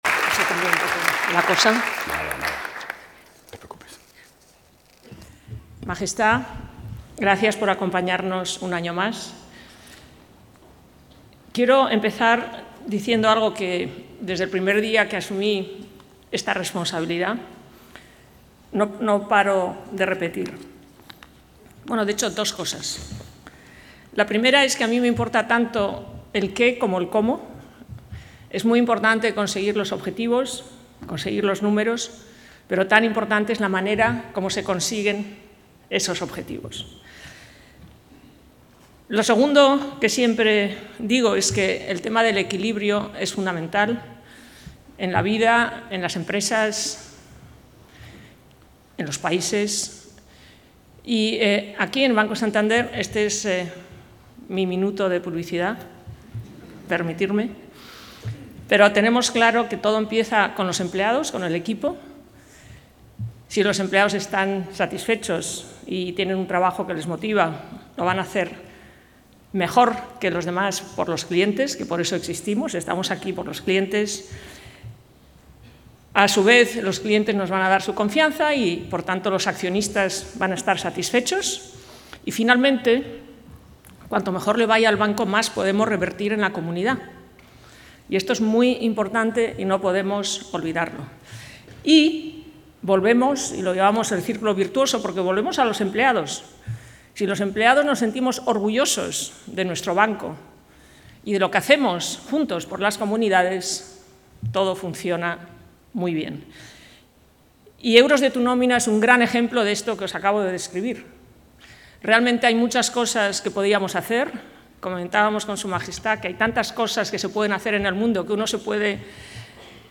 Discurso Ana Botín
Su Majestad la Reina ha presidido esta mañana la entrega de premios de la XVII Convocatoria de “Euros de tu Nómina”, que se ha celebrado en el auditorio de la Ciudad Grupo Santander.